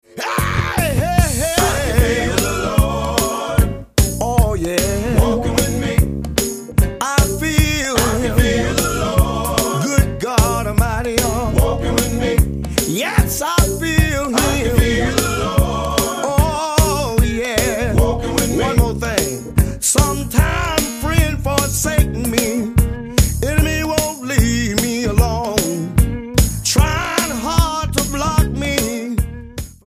STYLE: Gospel
Sharp production gives it a strong sound